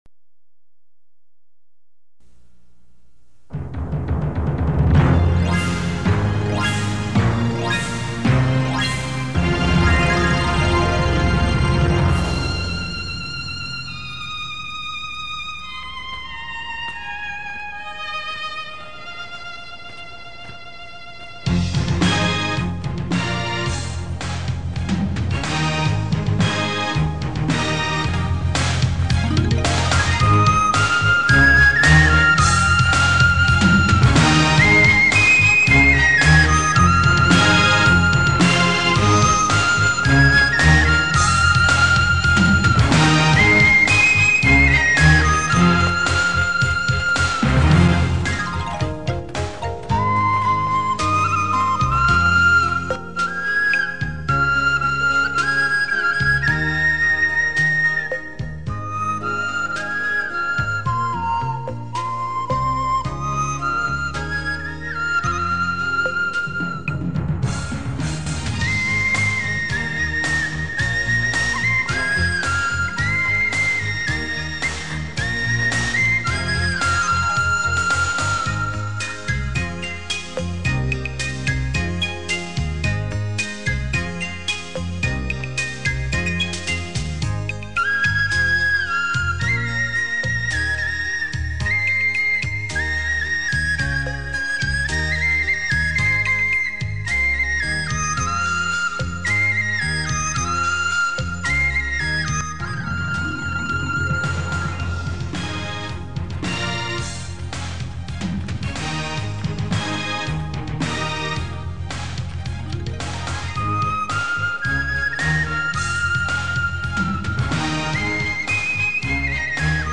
里面的配乐让我认识了笛子原来是这么好听。